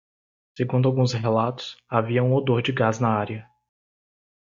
Read more odour; smell Frequency C2 Pronounced as (IPA) /oˈdoʁ/ Etymology Inherited from Old Galician-Portuguese odor (displacing collateral form olor), from Latin odōrem.